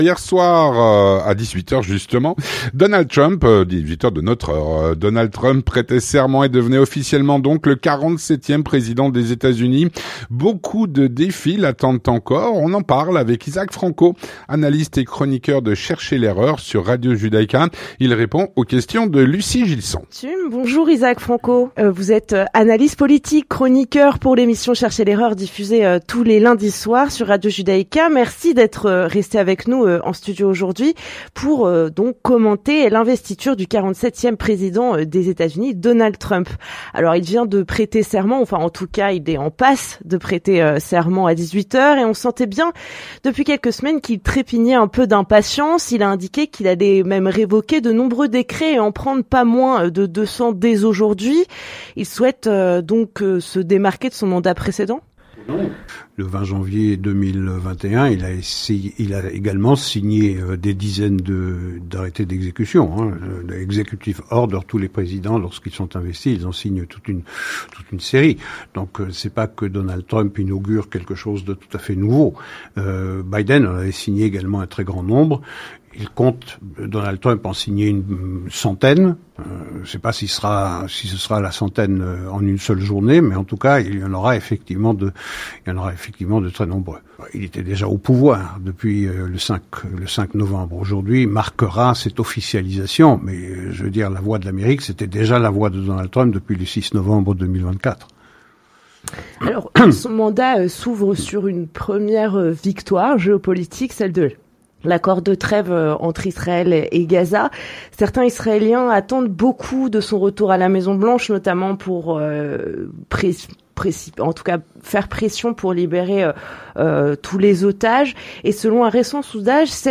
L'entretien du 18H - Donald Trump a prêté serment : beaucoup de défis l'attendent.